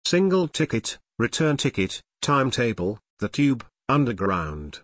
British English (Also Common)